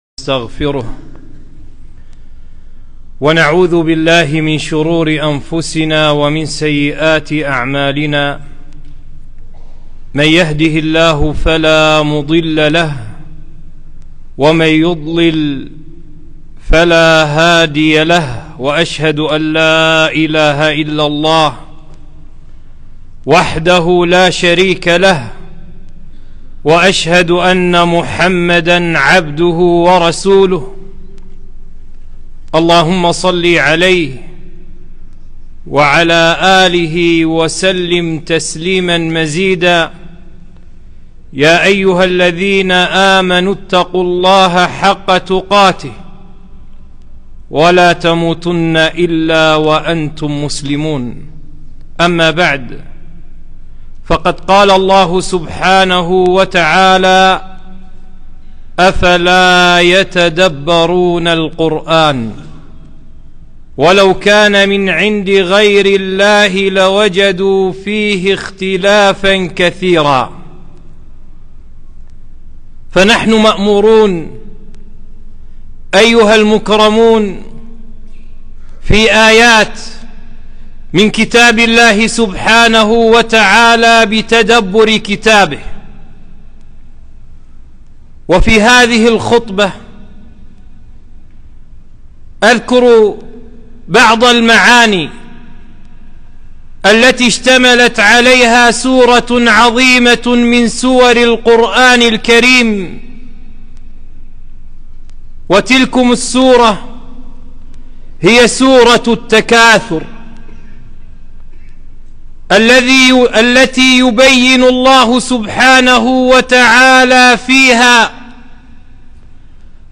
خطبة - التدبر بالقرآن الكريم (بعض معاني سورة التكاثر أنموذجا)